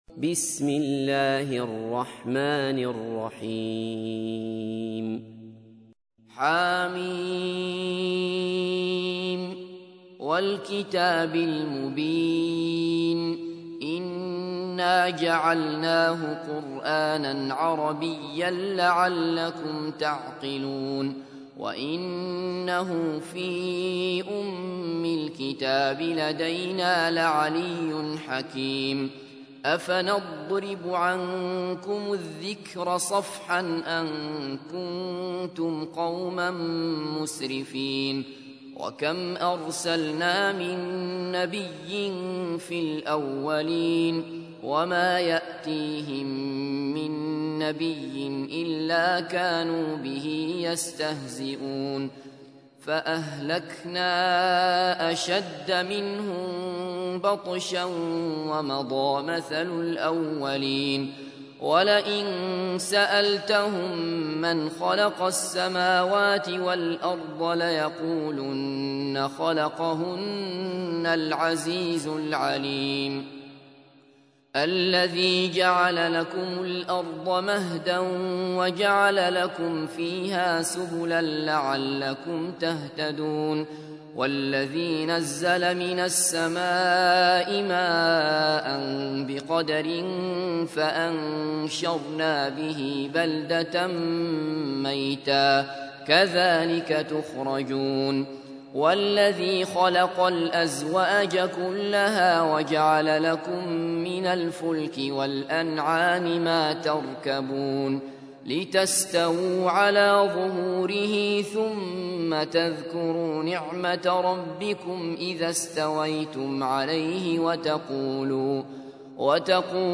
تحميل : 43. سورة الزخرف / القارئ عبد الله بصفر / القرآن الكريم / موقع يا حسين